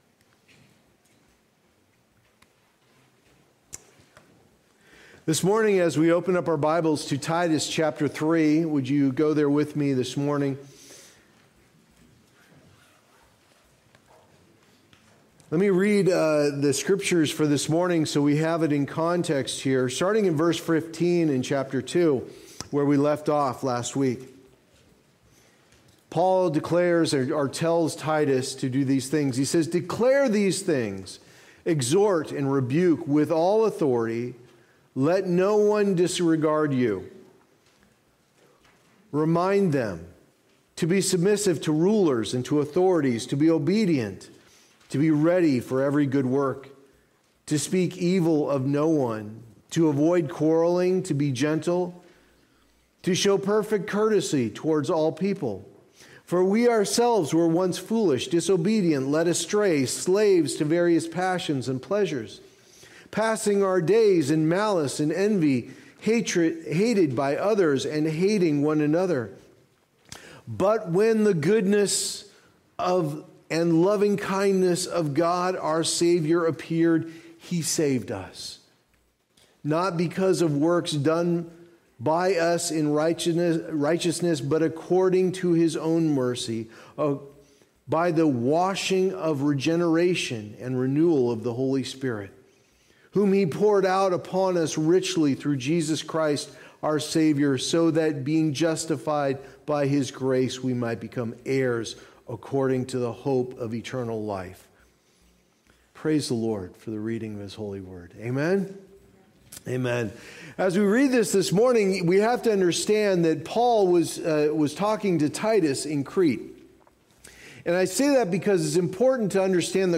Guard the Gospel & Shepherd the Flock Passage: Titus 2:15-3:7 Services: Sunday Morning Service Download Files Notes Previous Next